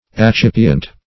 Search Result for " accipient" : The Collaborative International Dictionary of English v.0.48: Accipient \Ac*cip"i*ent\, n. [L. accipiens, p. pr. of accipere.